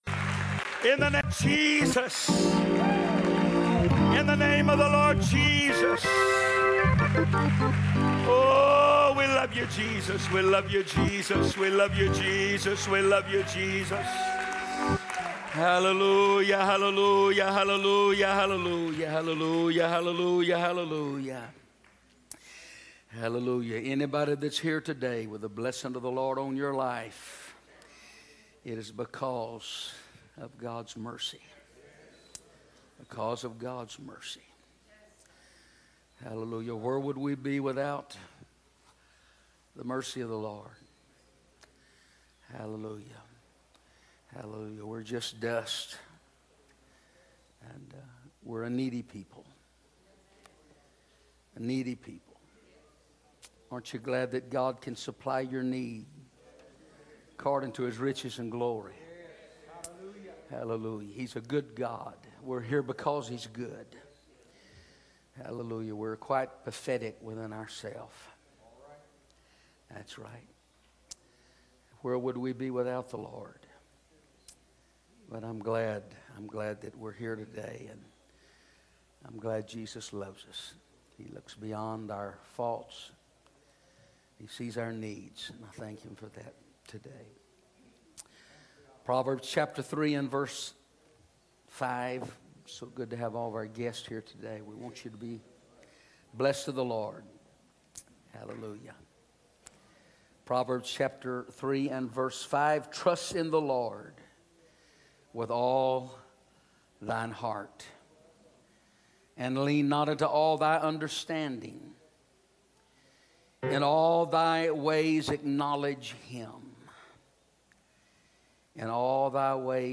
First Pentecostal Church Preaching 2018